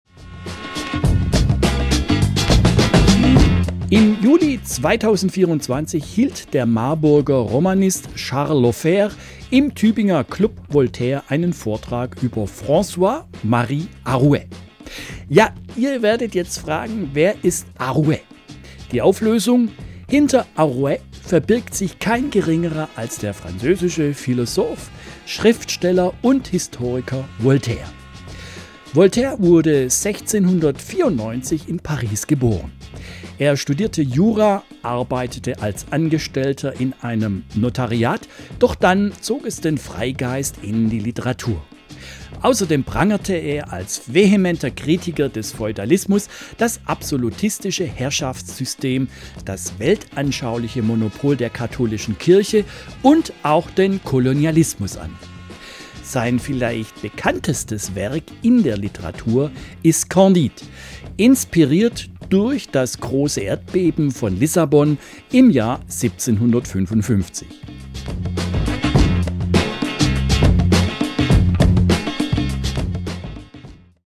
Es war Markttag, was im Hintergrund gut zu hören ist.